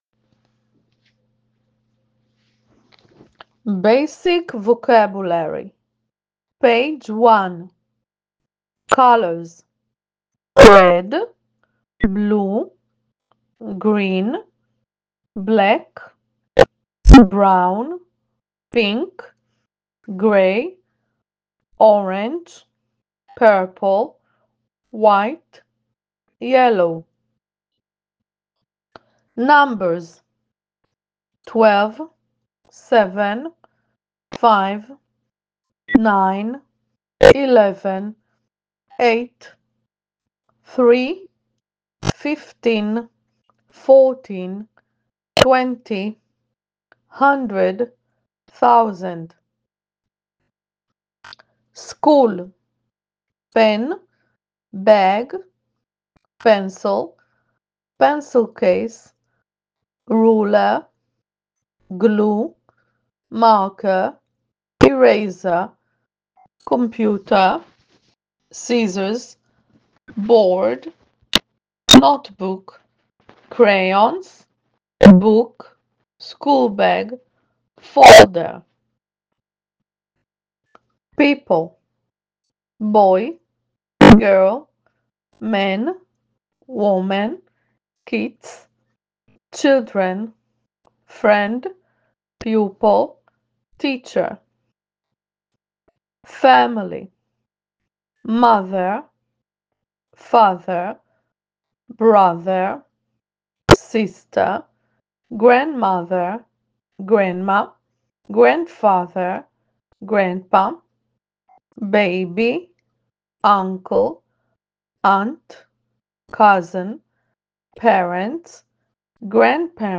הקלטה של המילים - אוצר מילים בסיסי